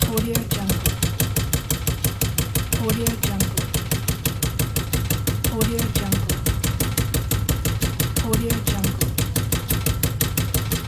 Fire_Ammo_1.mp3